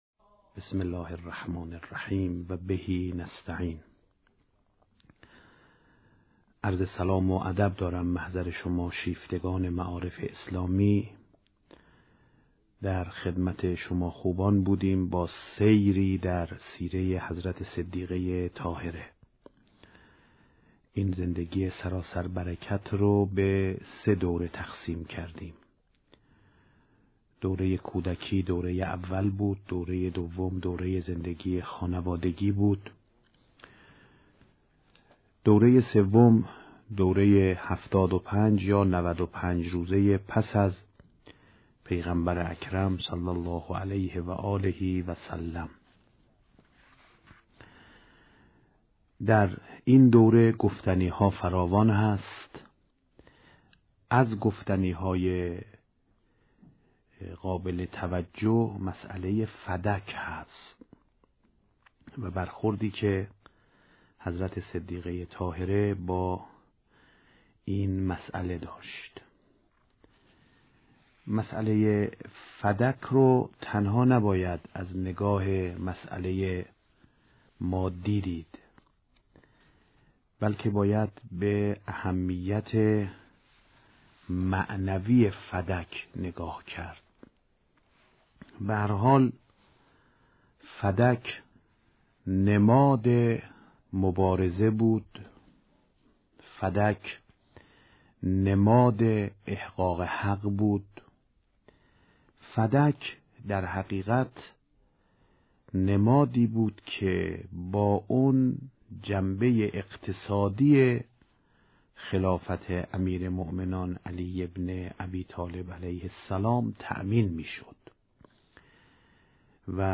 سخنرانی «آیت الله سید احمد خاتمی» با موضوع «فدک کجاست؟» (24:30)